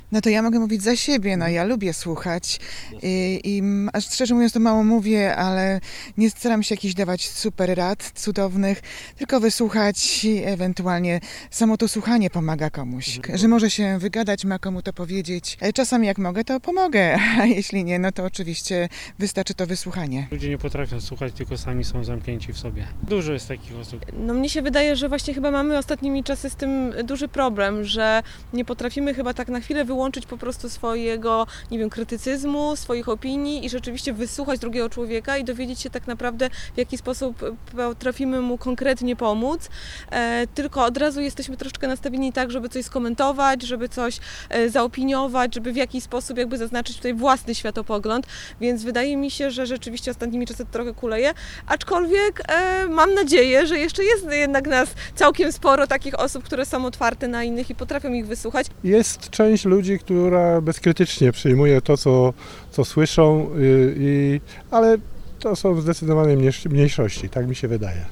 Przy okazji Światowego Dnia Słuchania zapytaliśmy mieszkańców Wrocławia i osoby odwiedzające nasze miasto o opinię nt. tego, co może wpływać na zamykanie się na rozmowę z innym człowiekiem: